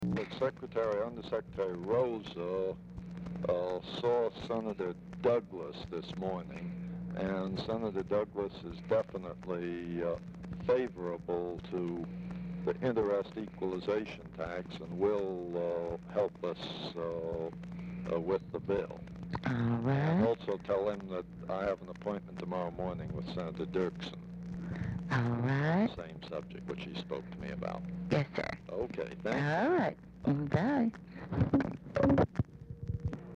Telephone conversation # 4189, sound recording
Format Dictation belt
Specific Item Type Telephone conversation